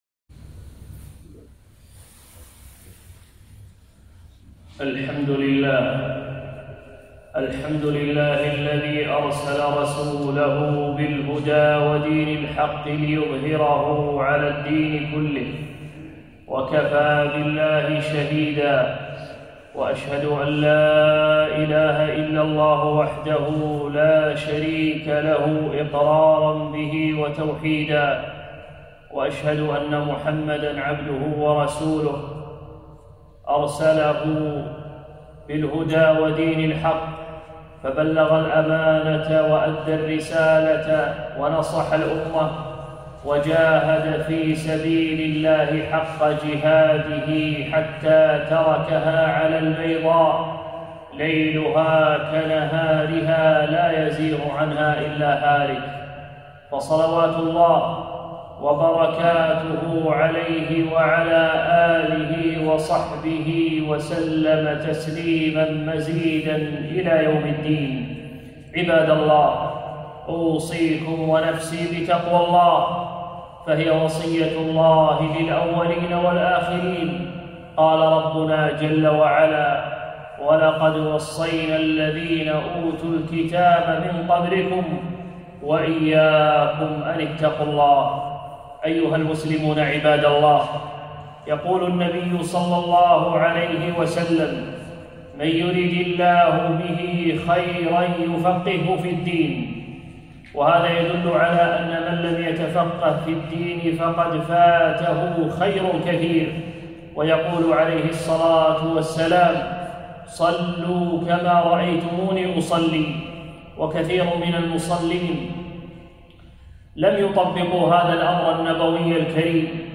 خطبة - من أخطاء المصلين